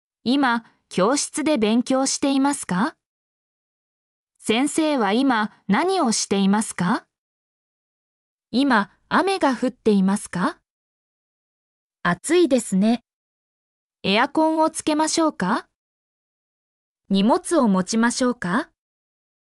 mp3-output-ttsfreedotcom-22_KC8SRP5A.mp3